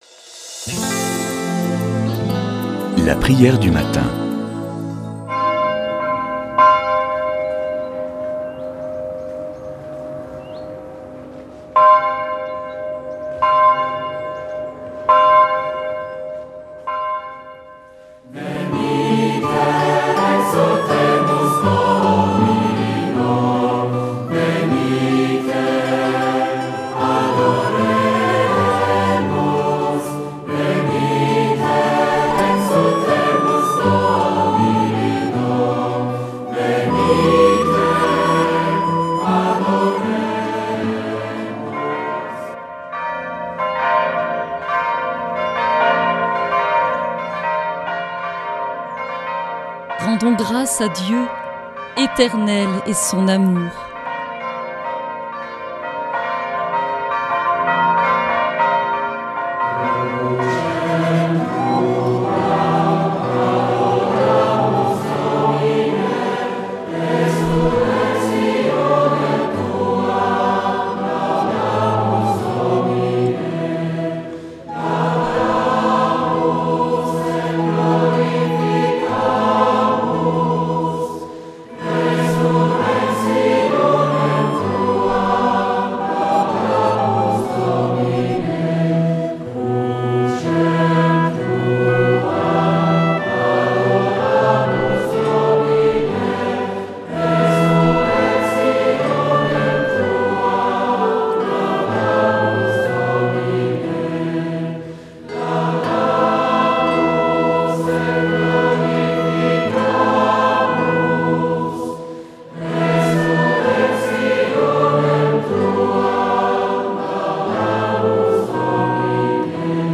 Prière du matin